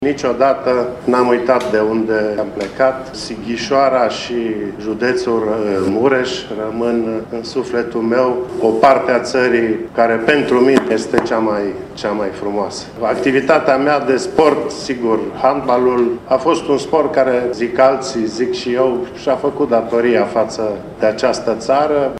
Ceremonia de premiere a fost organizată în Sala de Oglinzi a Palatului Culturii din Tîrgu-Mureș.